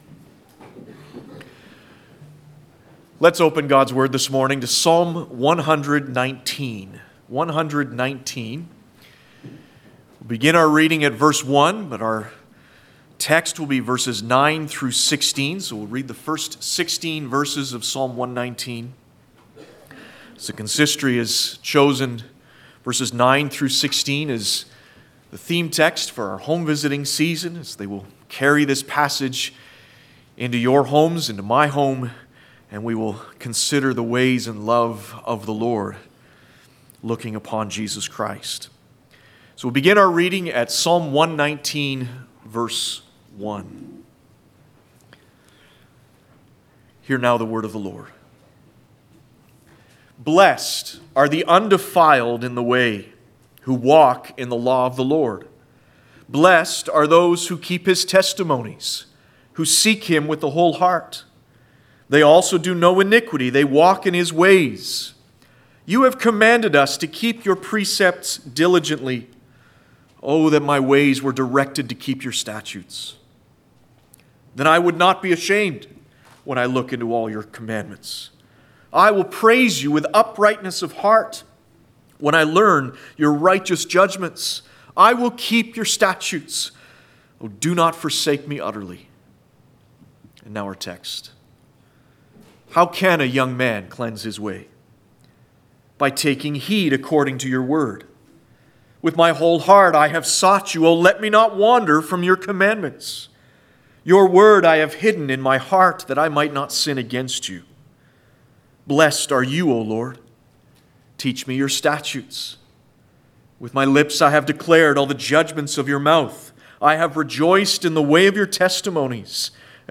Passage: Psalm 119:1-16 Service Type: Sunday Morning « God’s Light on Christ Jesus said